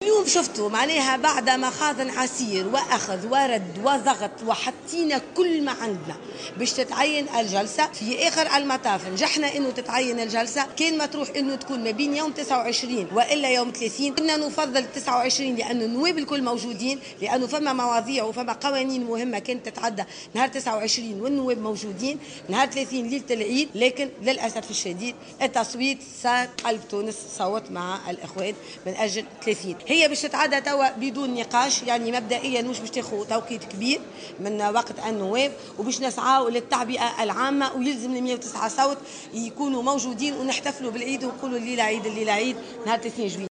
وقالت عبير موسي، في تصريح لمراسلة الجوهرة أف أم، اليوم الجمعة، إنها كانت ترغب في أن يتم اختيار يوم 29 موعدا لهذه الجلسة، نظار لكون يوم 30 جويلية سيوافق يوم عرفة، متهمة كتلة قلب تونس بالتصويت مجددا لصالح مقترح من أسمتهم "الإخوان" (حركة النهضة).